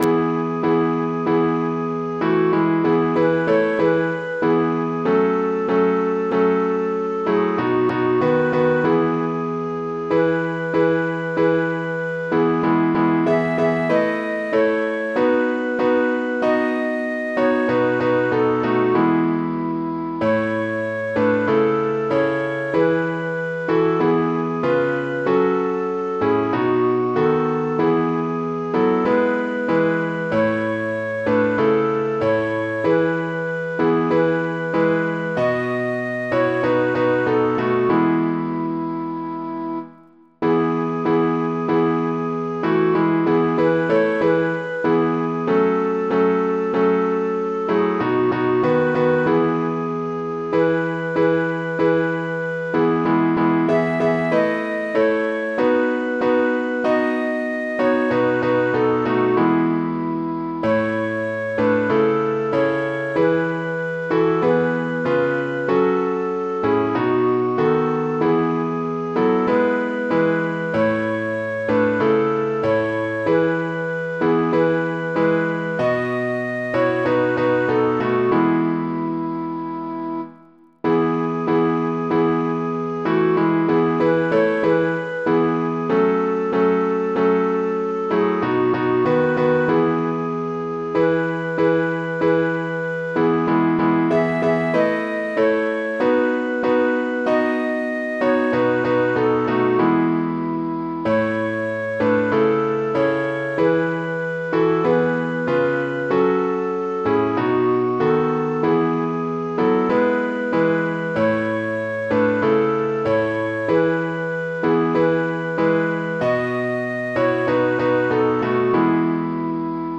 Духовни песни
piano, keyboard, keys
Мелодия за разучаване: